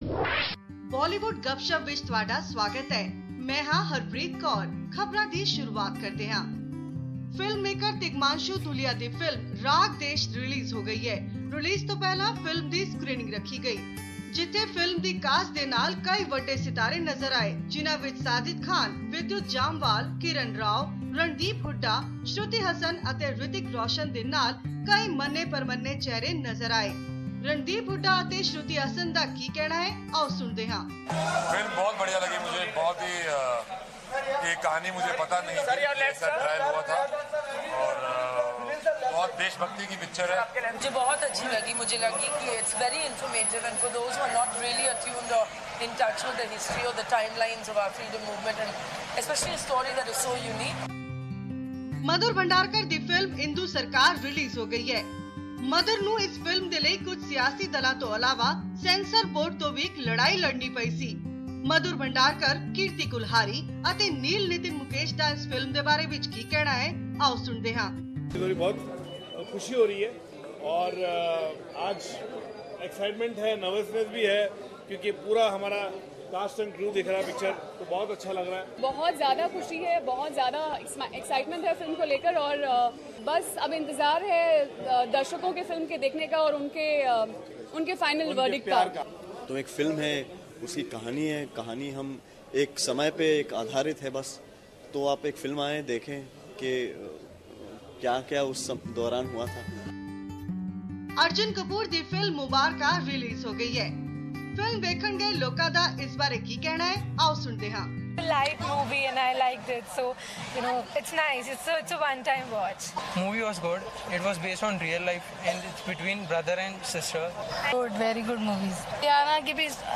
From Movie 'Mother's release to Sunny Deol's 'Poster boys', all the news you need to know from Bollywood this week. You'll hear from Randeep Hooda and Shruti Haasan about a recent release, as well as a report on Madhur Bhandarkar's Indu Sarkar